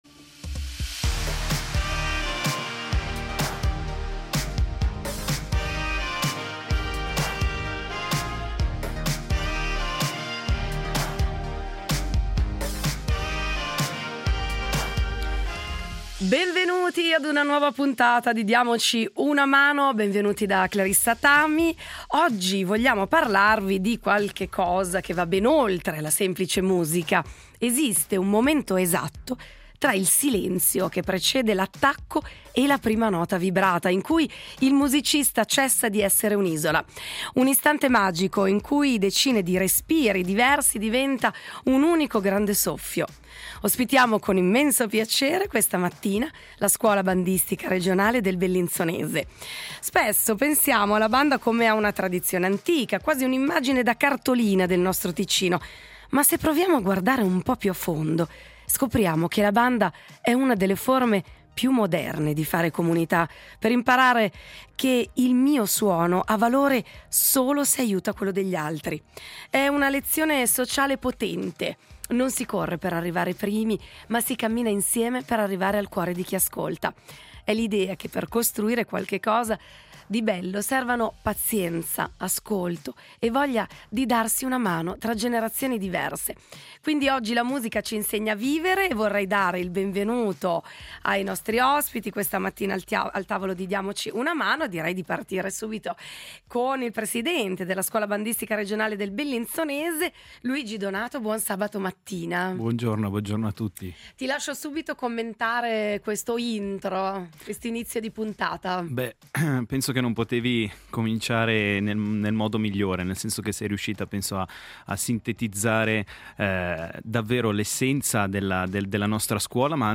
In studio le voci de la Scuola Bandistica Regionale del Bellinzonese .